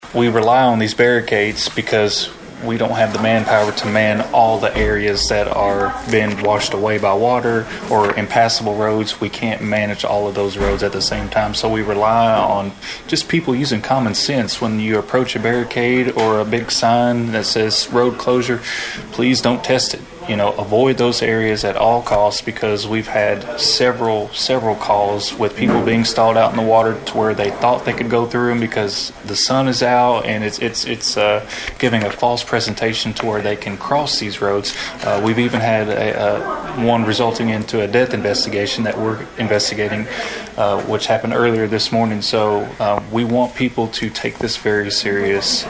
More than a dozen of Trigg County’s lead officials gathered Monday morning at the Emergency Operations Center on Jefferson Street, and all of them had the same message to send to the community.